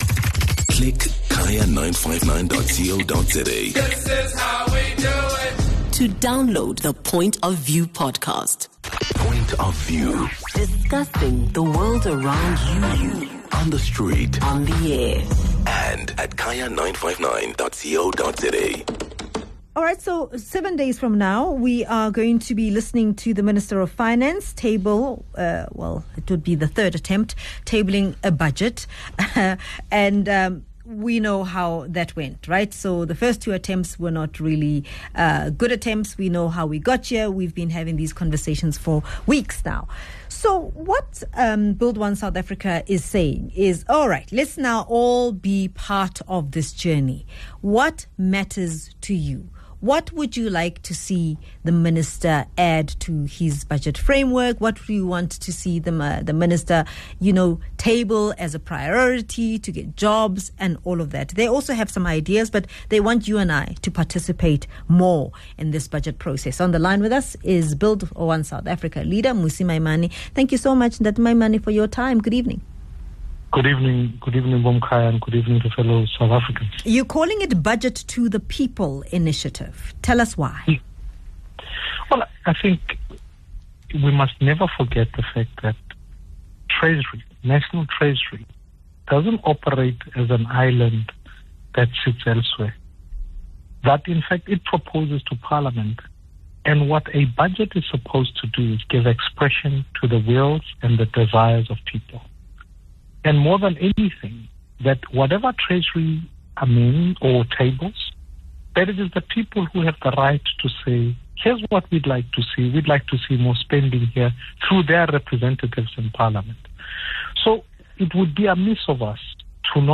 speaks to BOSA leader, Mmusi Maimane.